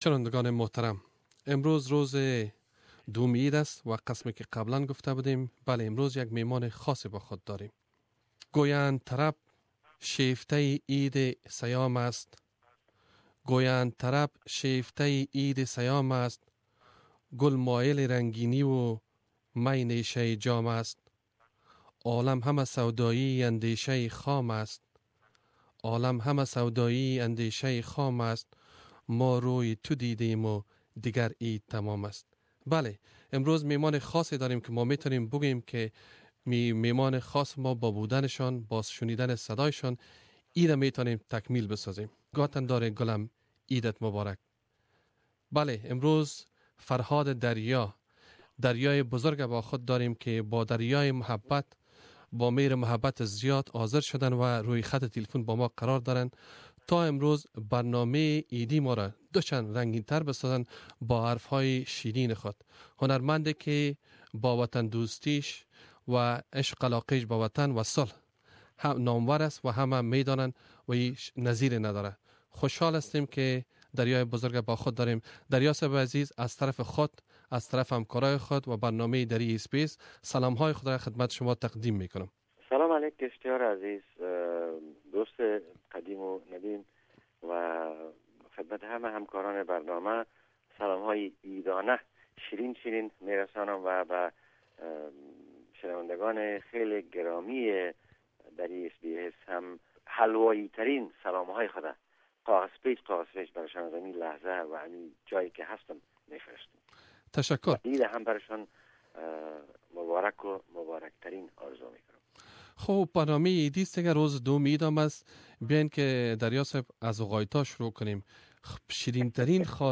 The Legendary Farhad Darya Co-Hosted Dari Program and shared his fondest Eid memories as well as talking about his World Tour 2016 and his view on both classic and modern music.